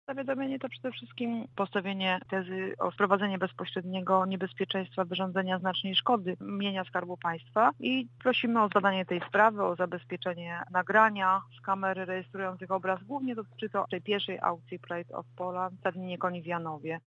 Więcej na ten temat mówi wiceszefowa sejmowej komisji rolnictwa i rozwoju wsi Dorota Niedziela z Platformy Obywatelskiej.